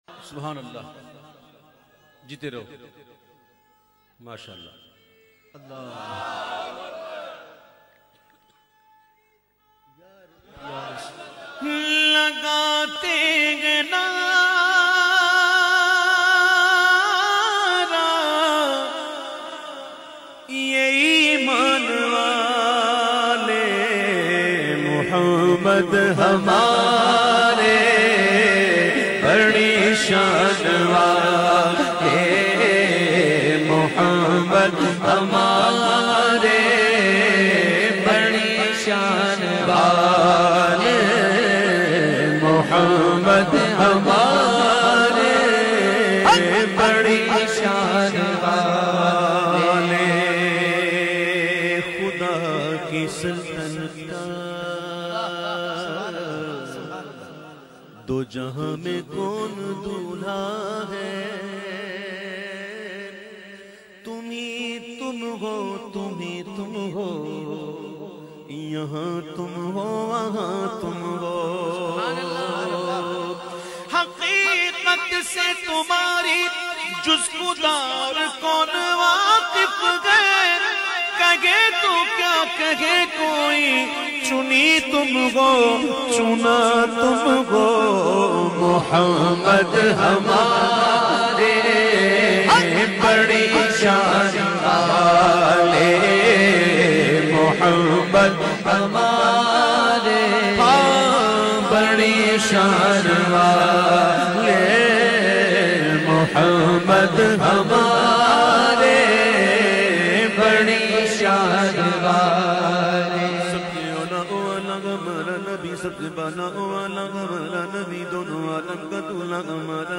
naat shareef